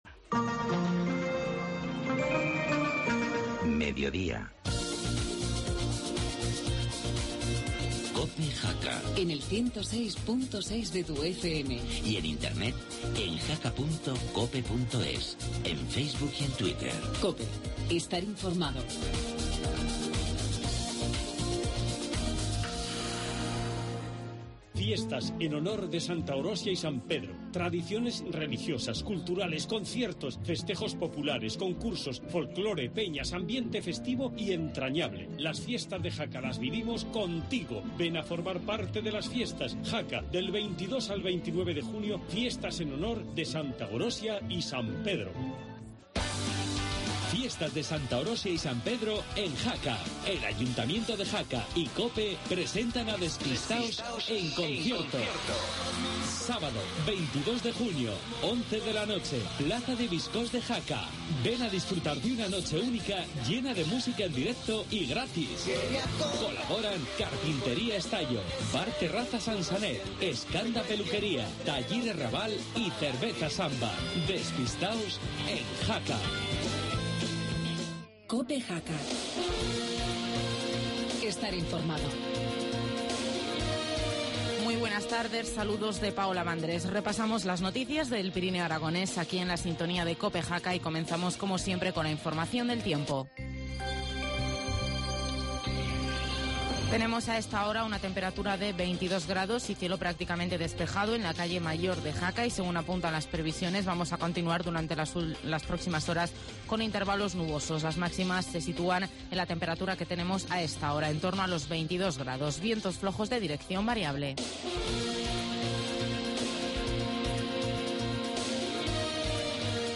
Informativo mediodía, martes 11 de junio